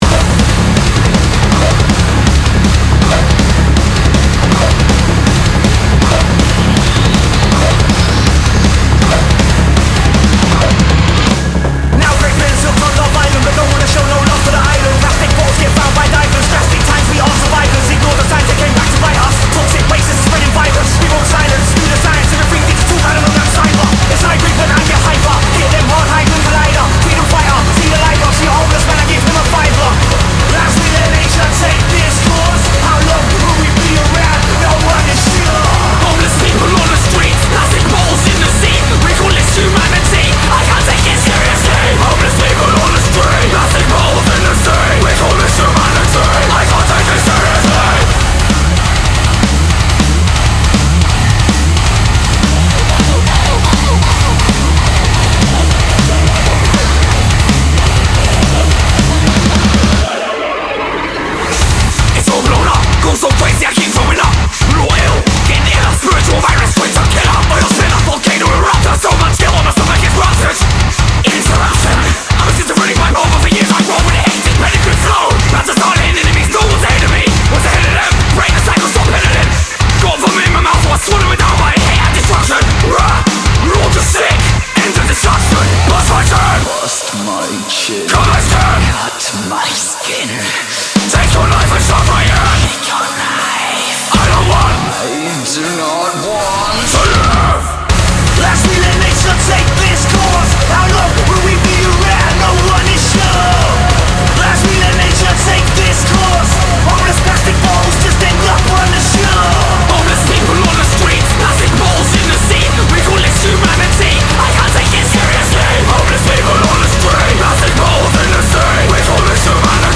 BPM160-320
Audio QualityCut From Video